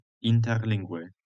Interlingue ([interˈliŋɡwe]